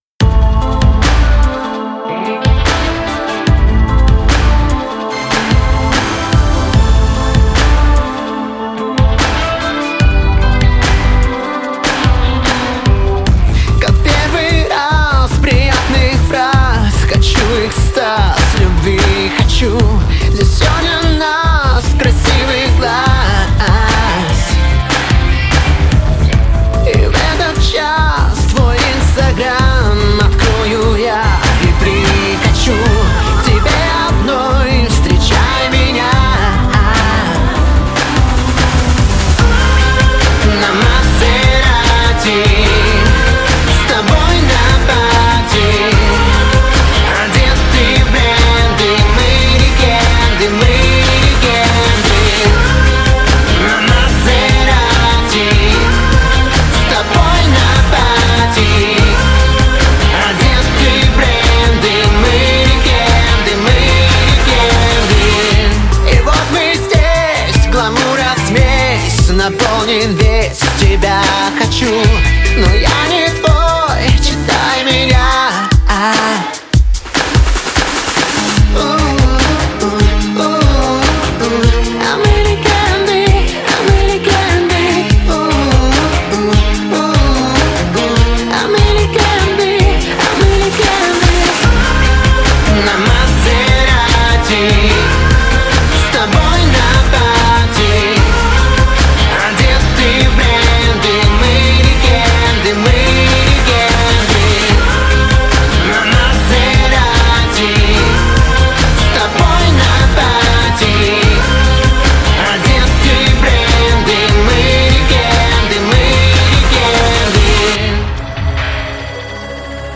Cтиль музыки- Synt-Pop-Rock.